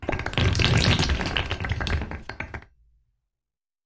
Звуки осьминога
Щупальца осьминога легко отлипают от стекла